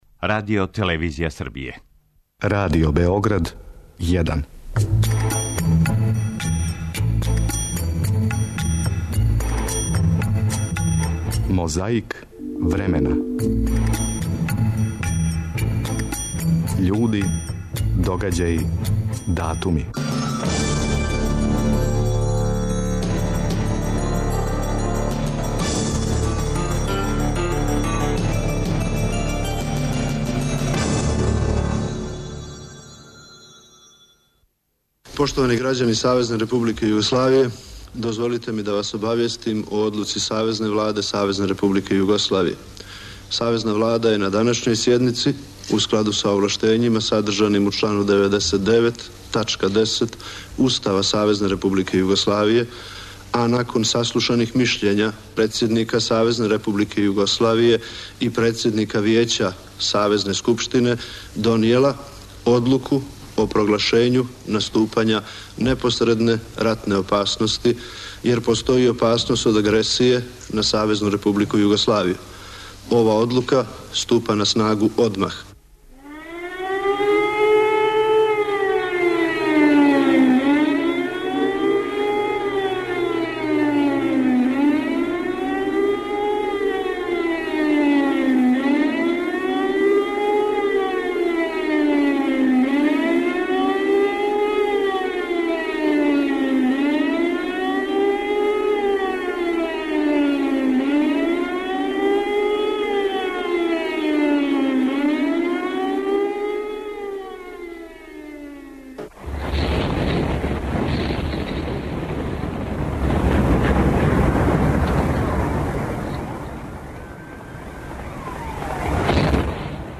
Обележавајући прву годишњицу бомбардовања 24. марта 2000. микрофон је забележио речи тадашњег министра Горана Матића.
Чућете део онога што је тих дана емитовано од 10 и 30 пре подне до касних вечерњих часова.